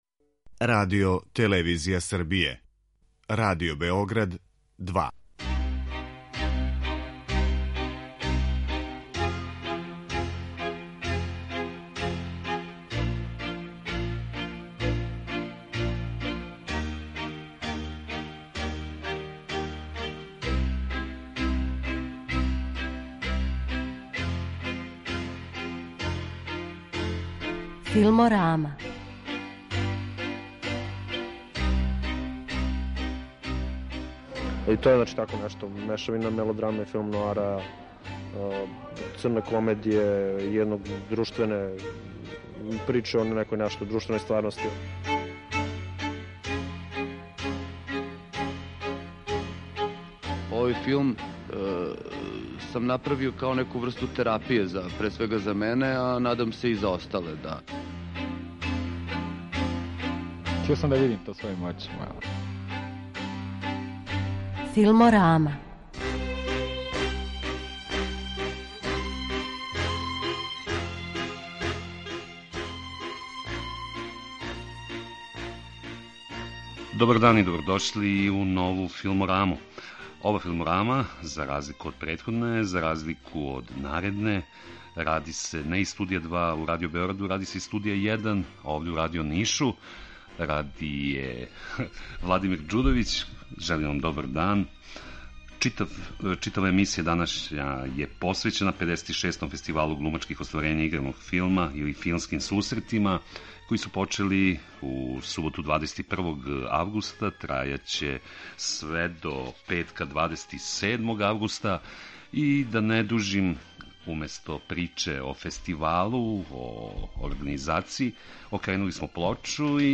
У току је 56. Фестивал глумачких остварења играног филма, на коме протагонисте девет домаћих играних остварења традиционално оцењује и награђује еснафски жири, драмски уметници који ове године немају филм у конкуренцији. Данашња емисија Филморама реализује се из Ниша, а у студију нашег дописништва саговорници ће нам бити челници, гости, учесници фестивала.